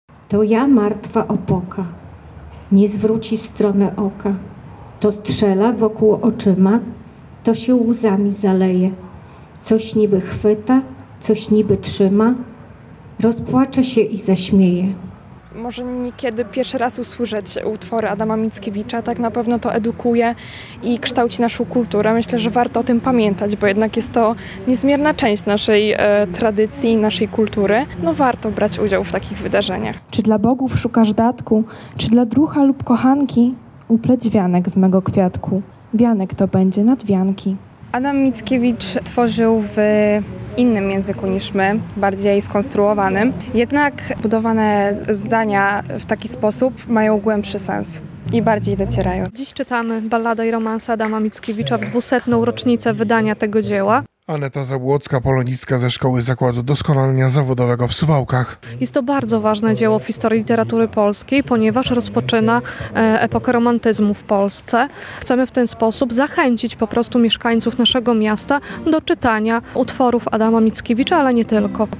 Ballady i romanse" Adama Mickiewicza czytali w piątek (02.09) w Parku Konstytucji 3 Maja uczniowie i nauczyciele szkoły Zakład Doskonalenia Zawodowego w Suwałkach.